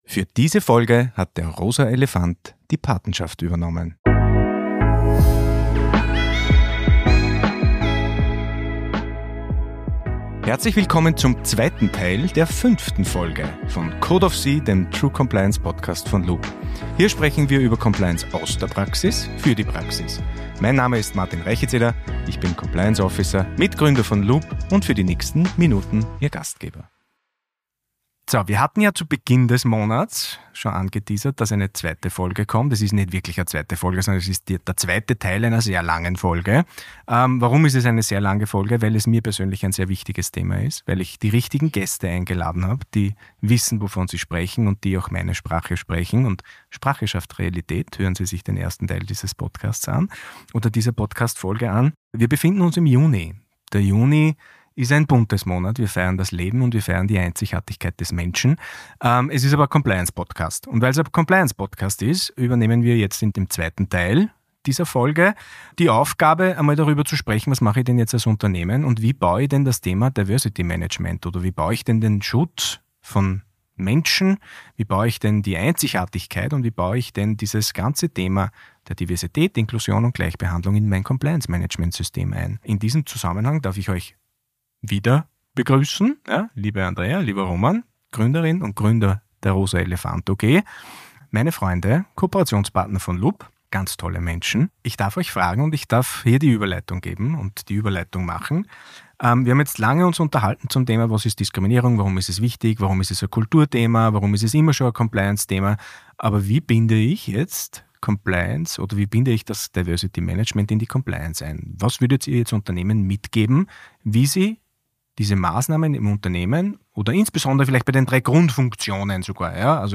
Auf Basis der Grundlagen der ersten Teil-Episode konzentrieren sie sich in dieser Folge darauf, wie konkrete Maßnahmen zum Schutz des bunten Lebens in das Compliance Management System integriert werden können. Neben der Rolle von Compliance wird die Einbindung anderer Verbündeter sowie die Bedeutung von Prävention (zB Schulungen) diskutiert. Die Gäste und Experten der rosa elefant OG teilen ihre Erfahrungen und geben praktische Tipps, um Unternehmen zu ermutigen, aktiv Maßnahmen zu ergreifen.